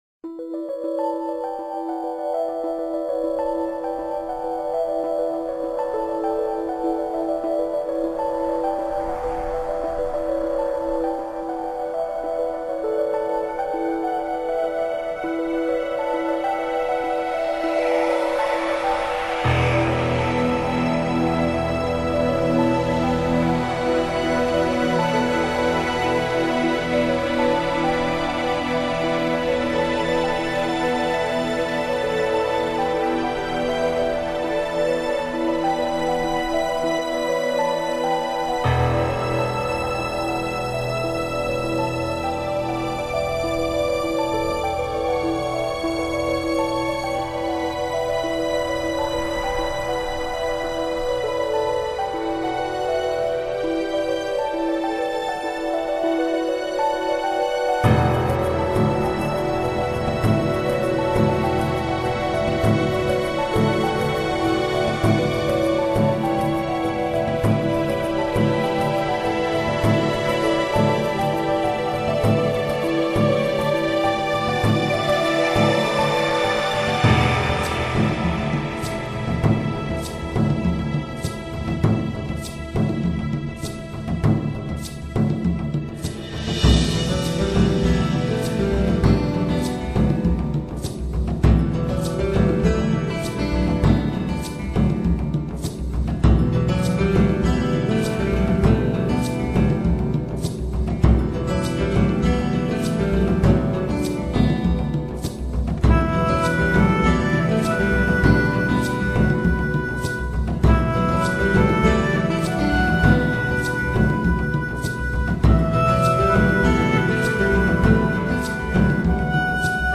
Genre: New Age
Styles: Alternative